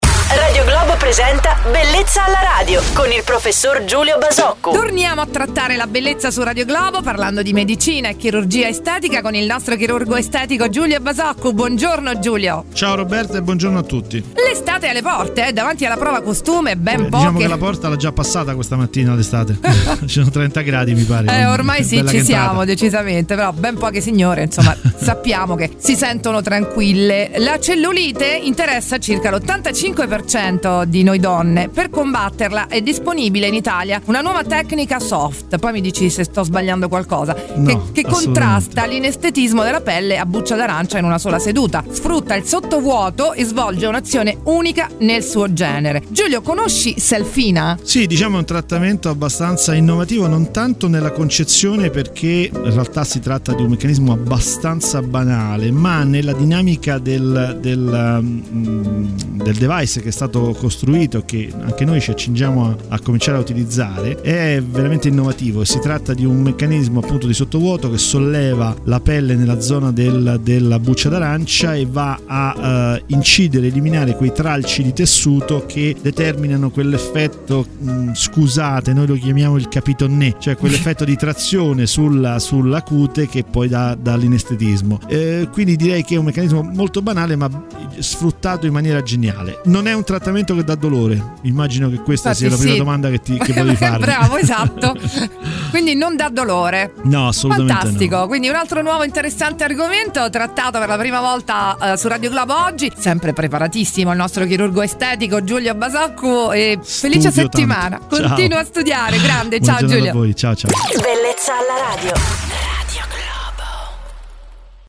BELLEZZA ALLA RADIO: La rubrica